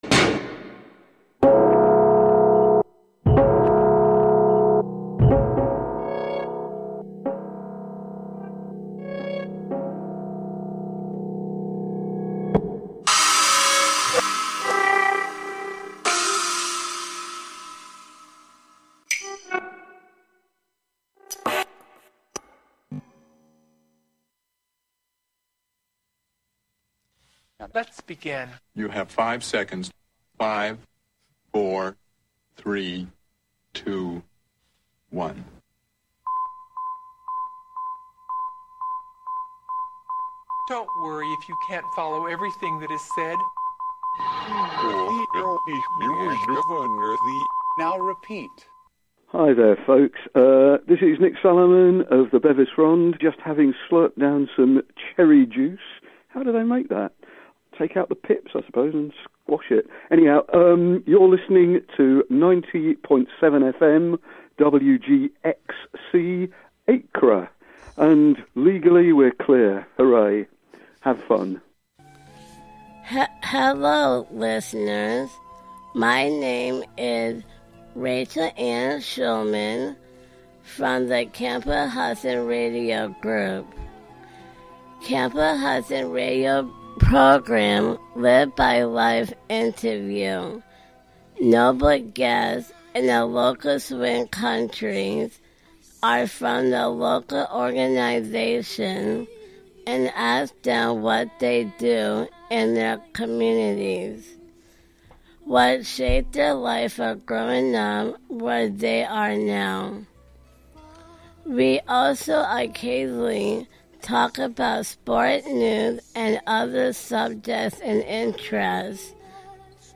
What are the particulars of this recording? "Auditions" features prospective WGXC volunteer programmers trying out their proposed radio programs on air for listener feedback.